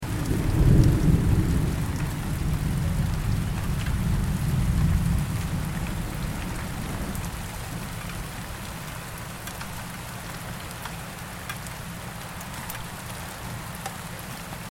دانلود آهنگ طوفان 15 از افکت صوتی طبیعت و محیط
جلوه های صوتی
دانلود صدای طوفان 15 از ساعد نیوز با لینک مستقیم و کیفیت بالا